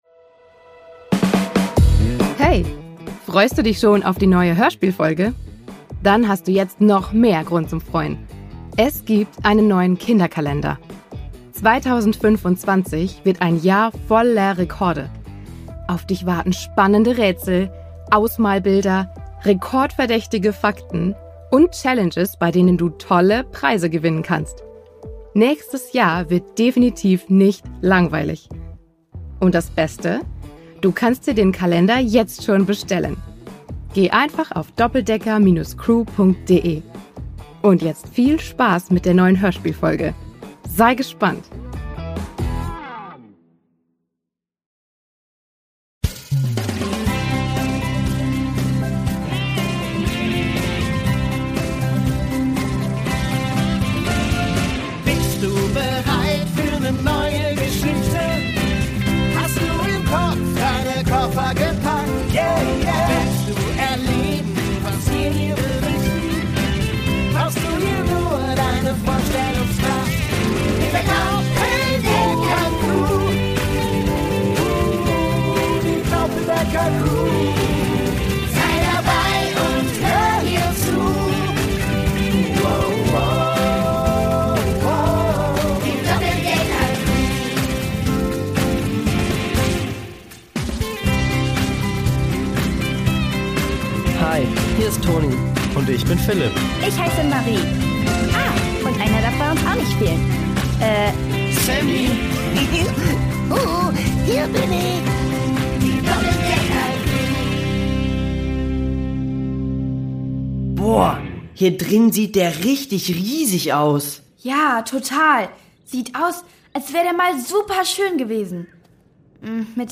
Brasilien 1: Verirrt im Dschungel | Die Doppeldecker Crew | Hörspiel für Kinder (Hörbuch) ~ Die Doppeldecker Crew | Hörspiel für Kinder (Hörbuch) Podcast